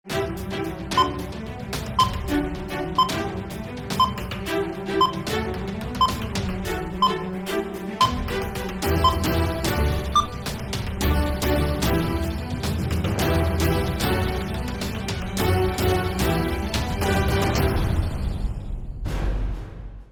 دانلود صدای ثانیه شمار 2 از ساعد نیوز با لینک مستقیم و کیفیت بالا
جلوه های صوتی